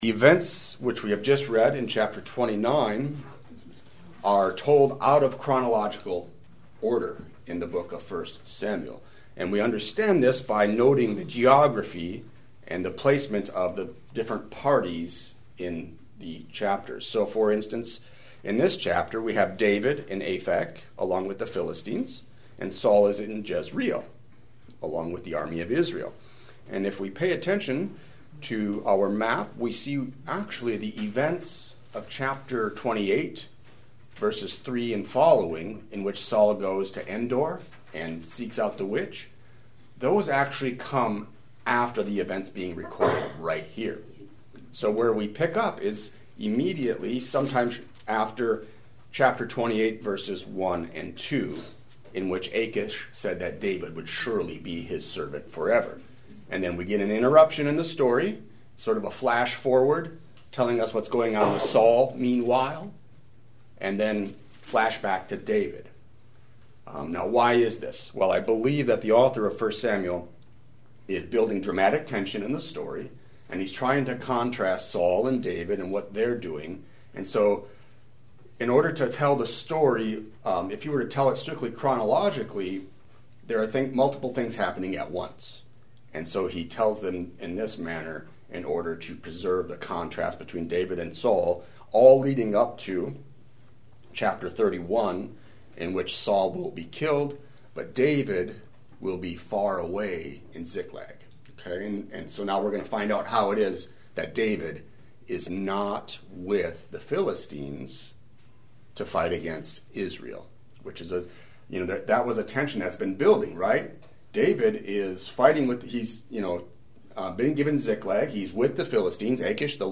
Sermon